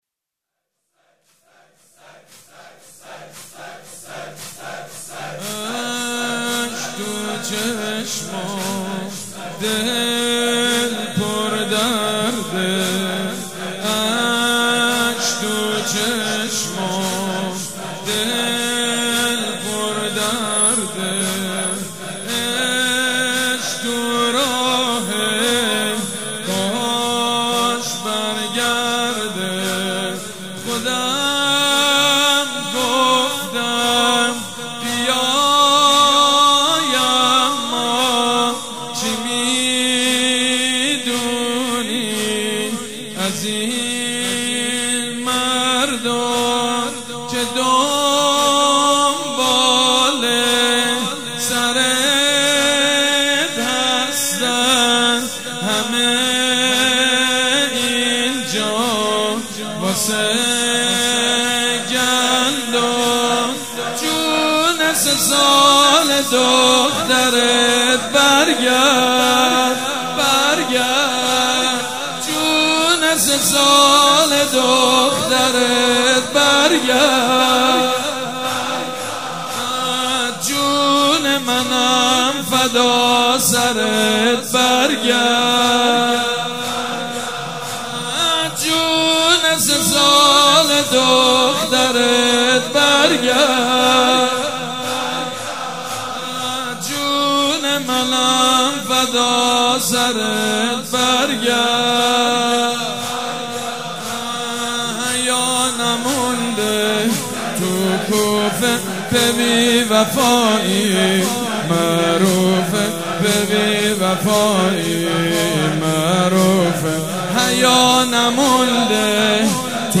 شب اول محرم الحرام
مداح
حاج سید مجید بنی فاطمه
مراسم عزاداری شب اول